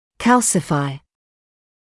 [‘kælsɪfaɪ][‘кэлсифай]обызвествлять(ся), отвердевать; подвергаться кальцинозу